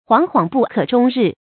huáng huáng bù kě zhōng rì
惶惶不可终日发音